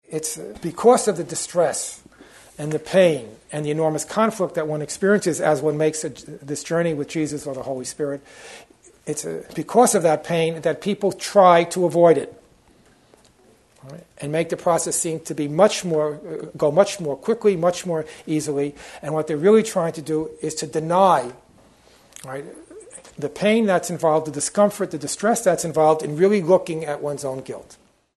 The theme of this workshop, held in September 1998, is the exploration of the six stages in the development of trust discussed in the section, “What Are the Characteristics of God’s Teachers?” These stages represent the transitions experienced in the process of shifting one’s trust in the ego’s offerings to total trust in the offerings of Jesus or the Holy Spirit, culminating in the attainment of the real world.